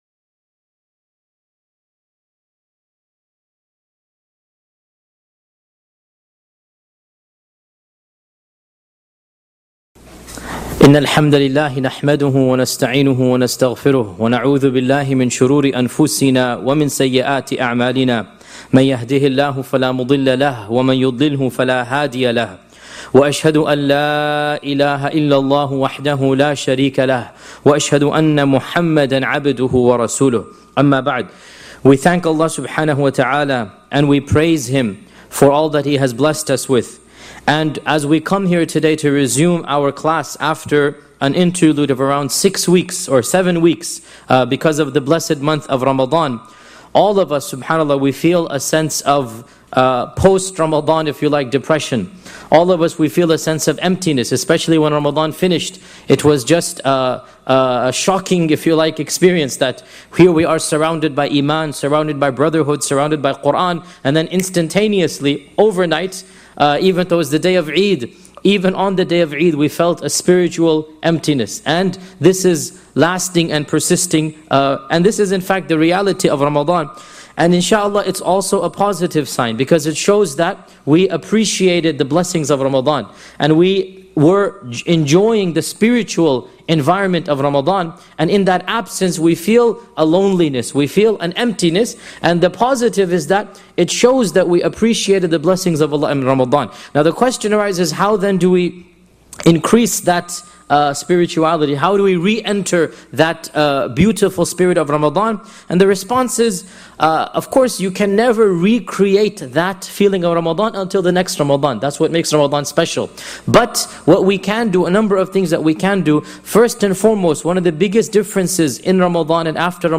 This Seerah lecture resumes after Ramadan with a powerful discussion about maintaining spiritual momentum, followed by an exploration of the post-Ramadan period and the resumption of the Prophet’s biography. The lecture covers the importance of staying connected to the mosque, the Quran, and beneficial knowledge as tools for sustaining the spiritual highs experienced during the blessed month.